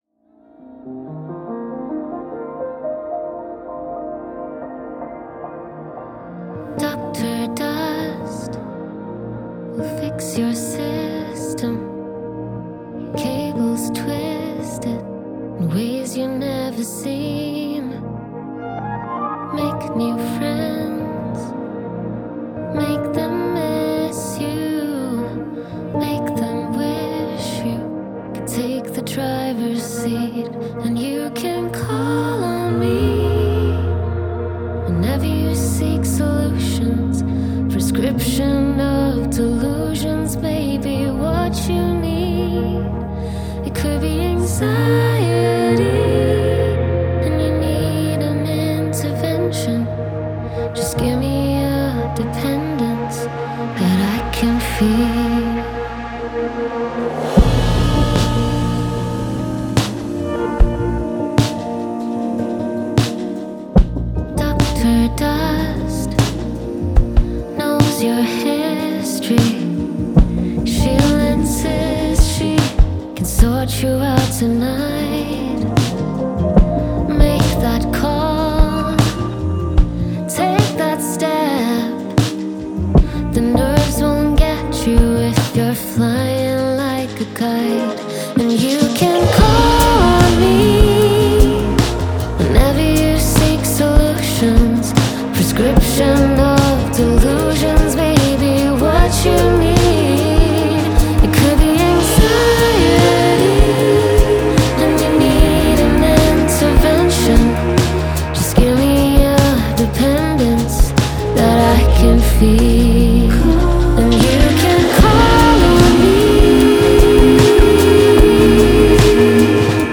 Drums
Bass
Guitars
Backing Vocals